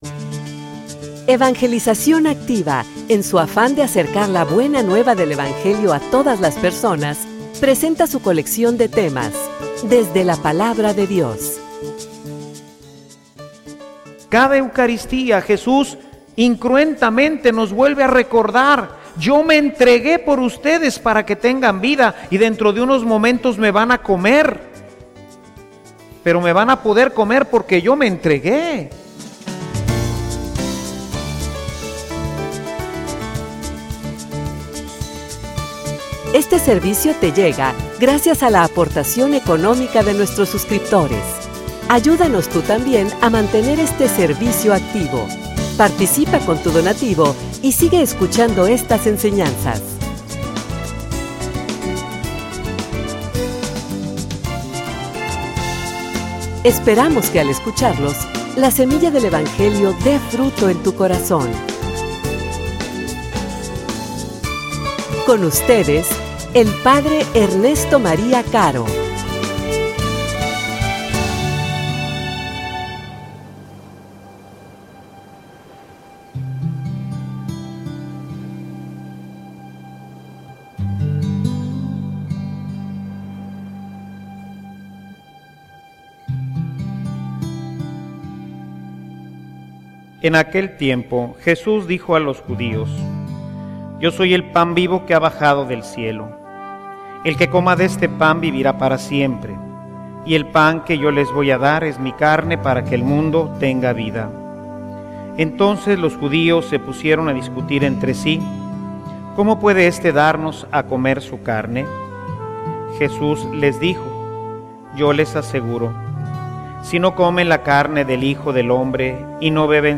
homilia_Pan_para_los_demas.mp3